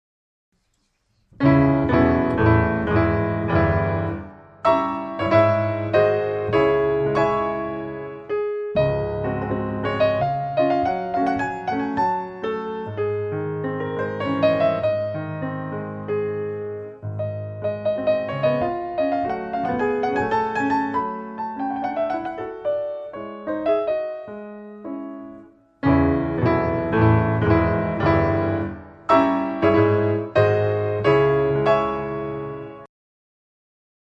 Polonaise Op. 26 No. 1 starts with 4 sharps (C# minor) and moves to 5 flats (Db major)
polonaisesample.mp3